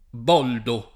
vai all'elenco alfabetico delle voci ingrandisci il carattere 100% rimpicciolisci il carattere stampa invia tramite posta elettronica codividi su Facebook Boldo [ b 0 ldo ] pers. m. stor. (= Ubaldo) — sim. il cogn.